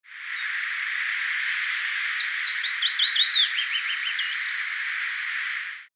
2004: Hybride fitis x tjiftjaf?
Er staan acht stukjes geluid op met telkens een seconde wit ertussen, dit is een selectie uit vijf minuten geluid.
Wat zwaarder gefilterde stukjes laten horen dat het soms